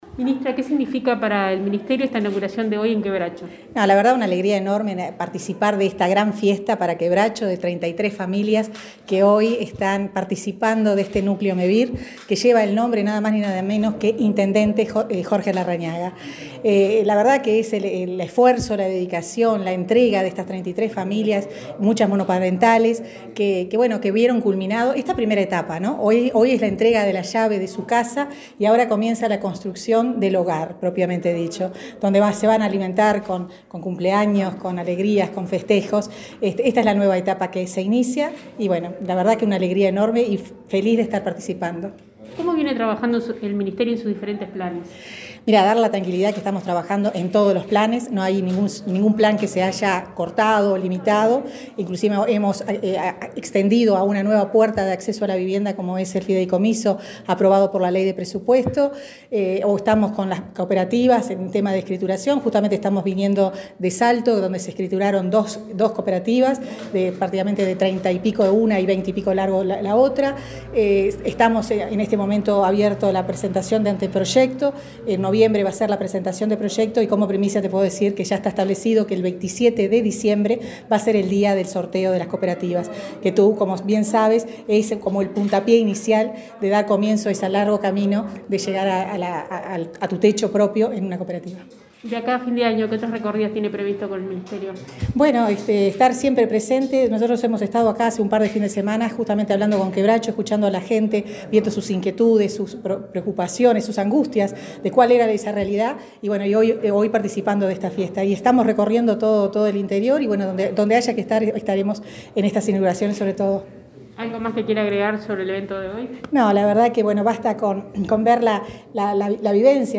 Declaraciones a la prensa de la ministra de Vivienda y Ordenamiento Territorial, Irene Moreira | Presidencia Uruguay
Tras participar en la inauguracion de complejo habitacional Doctor Jorge Larrañaga, en Quebracho, Paysandú, este 28 de octubre, Moreira efectuó